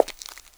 PAVEMENT 3.WAV